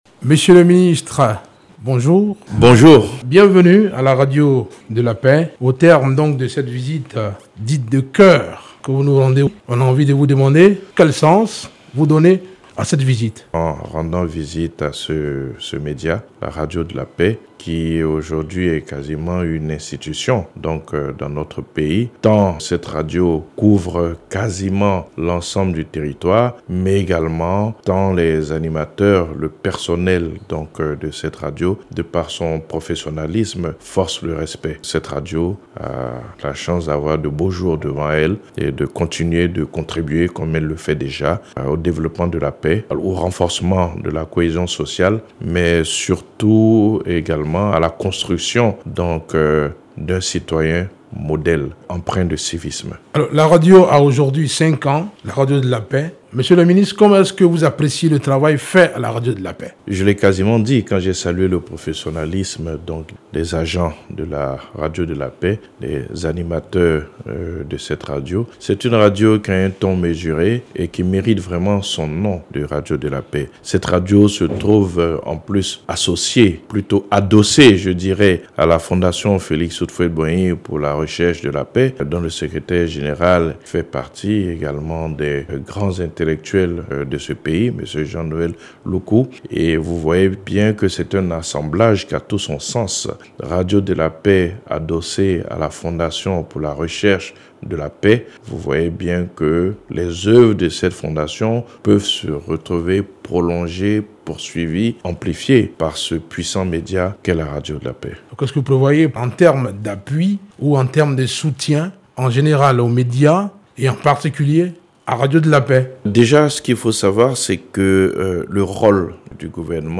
Notre invite ce matin est le Ministre de la Communication et de l’Économie Numérique, Amadou Coulibaly.